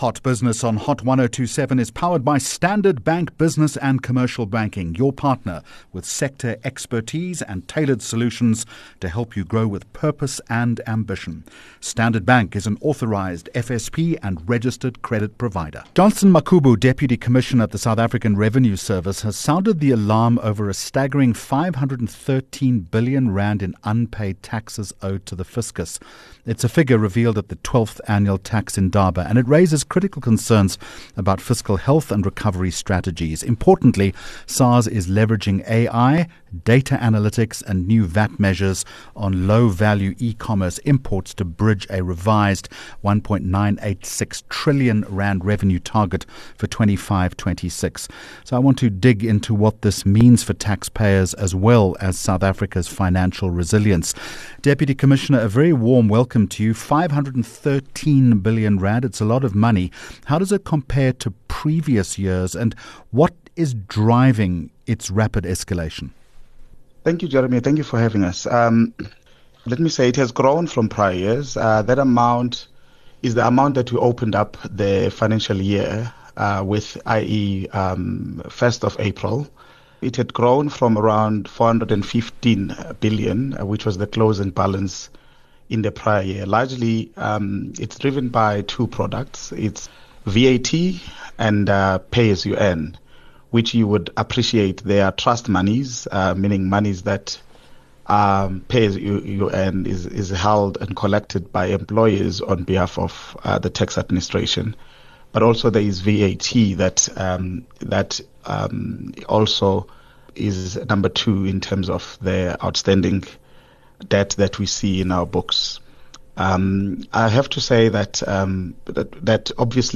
12 Aug Hot Business interview - Johnstone Makhubu 12 August 2025